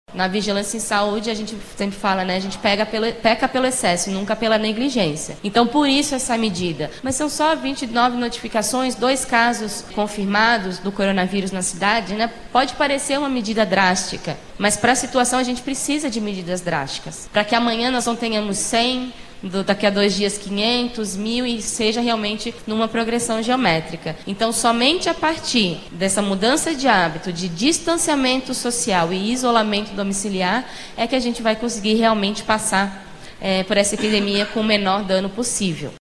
O assunto foi um dos pontos abordados na audiência realizada na Câmara Municipal, nesta quarta-feira, 18.